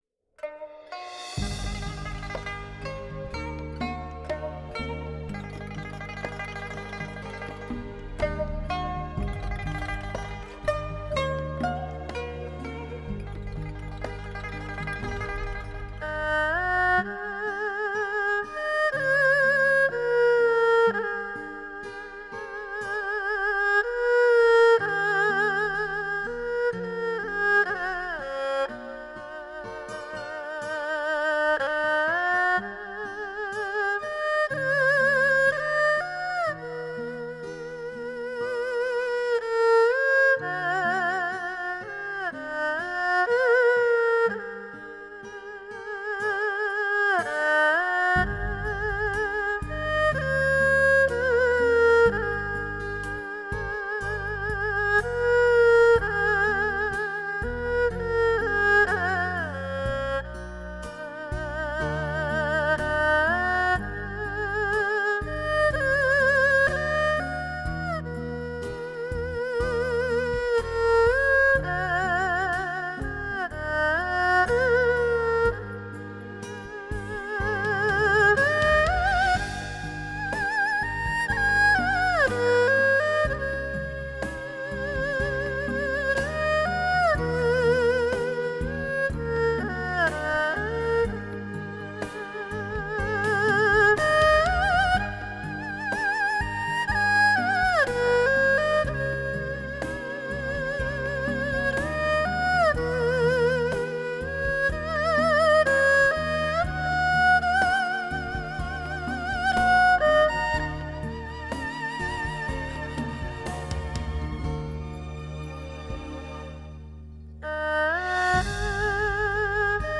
技术成就与人声的骄傲，国际新锐科技的结晶，震撼梦幻的音乐盛宴，无法想象的听觉感受，高级音响专用环绕声演示碟。
分离七声道定位无误差数码音频